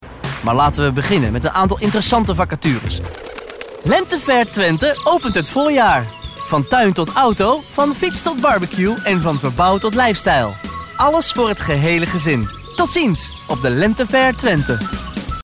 Grabaciones en nuestro estudio de sonido asociado de Holanda.
Locutores holandeses